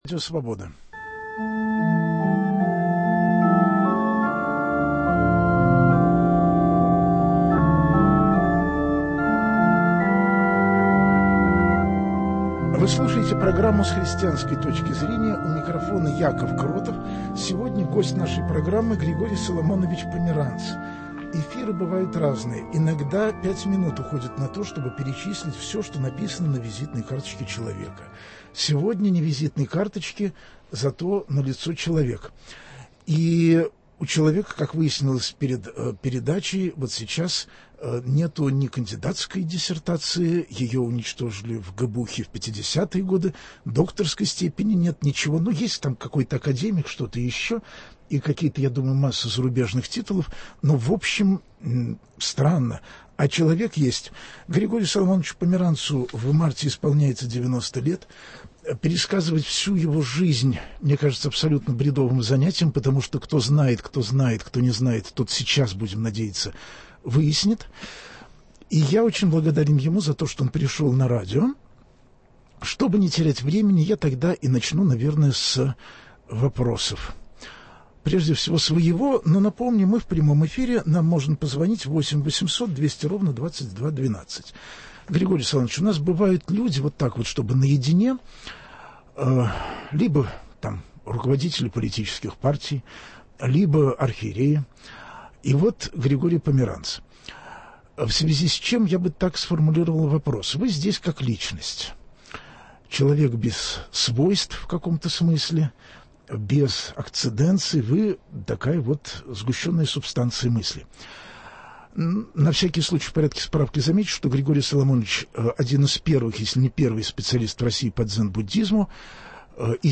Гостем программы будет Григорий Соломонович Померанц, востоковед, историк, специалист и по истории религии.